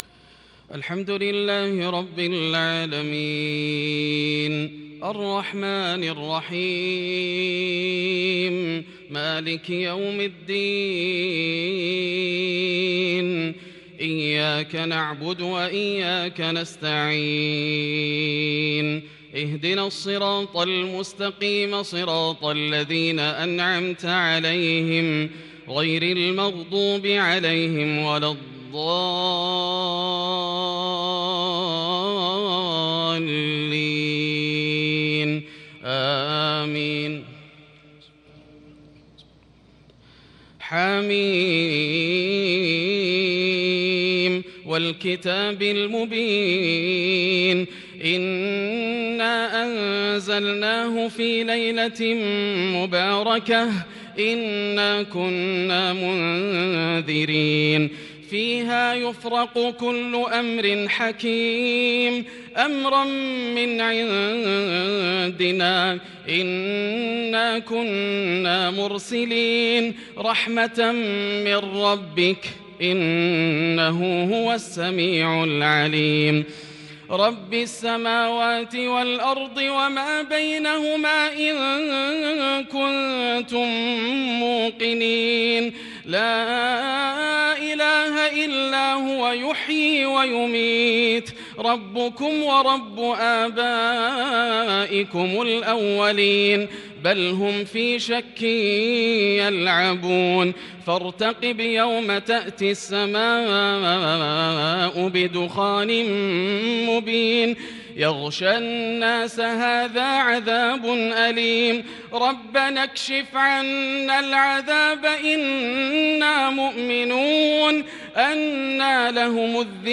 تراويح ليلة 27 رمضان 1441هـ من سورة الدخان الى الأحقاف | taraweeh 27 st niqht Ramadan 1441H from Surah Ad-Dukhaan to al ahqaf > تراويح الحرم المكي عام 1441 🕋 > التراويح - تلاوات الحرمين